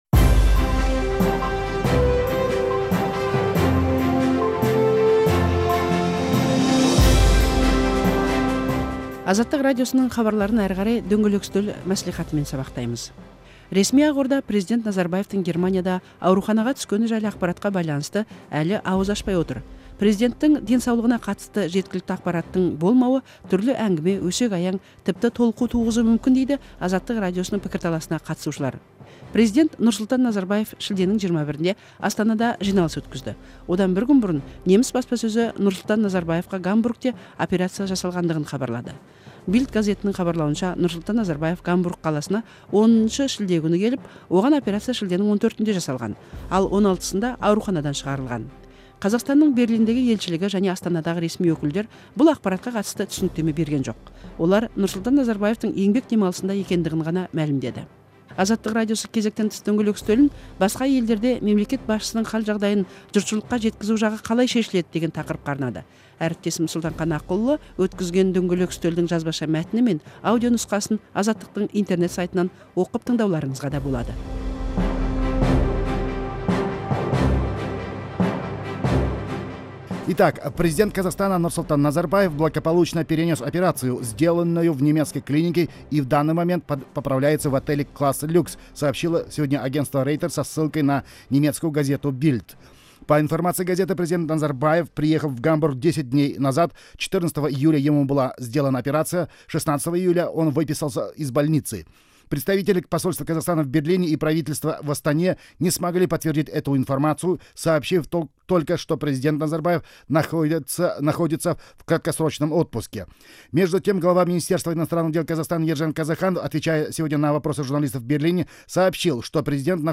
Қазақстан президентінің денсаулығына қатысты сұқбатты тыңдаңыз